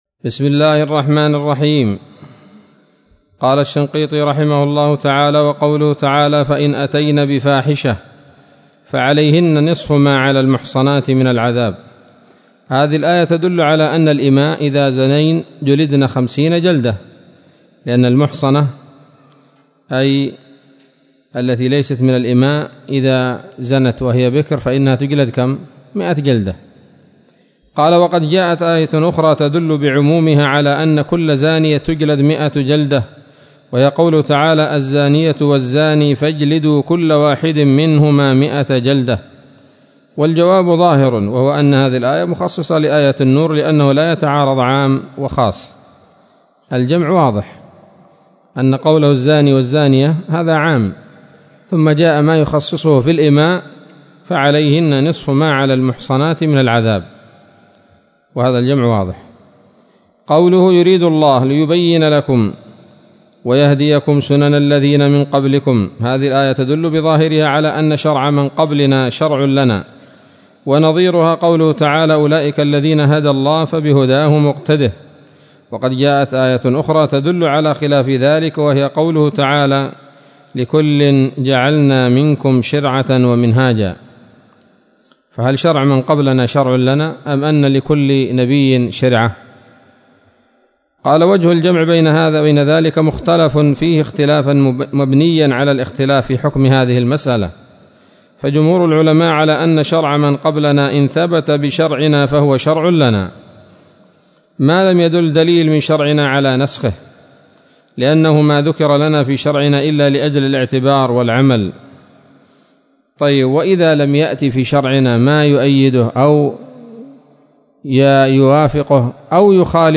الدرس التاسع والعشرون من دفع إيهام الاضطراب عن آيات الكتاب